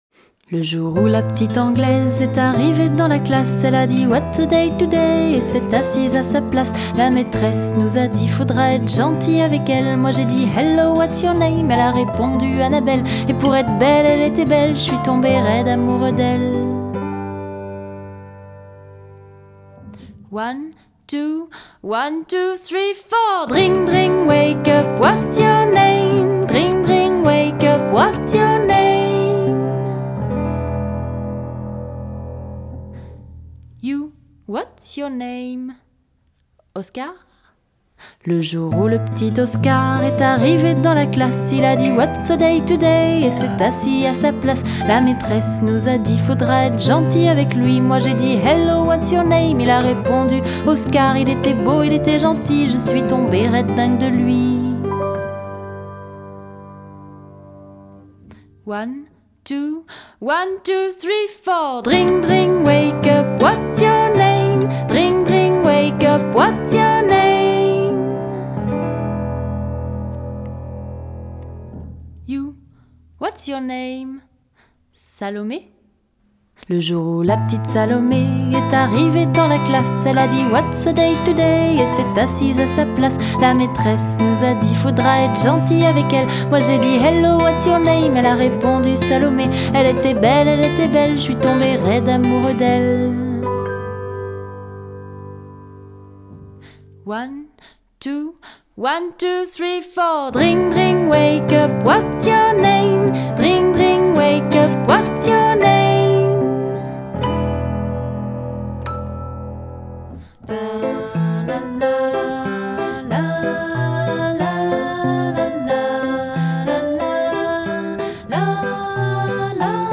De la chanson pour enfants comme on les aime !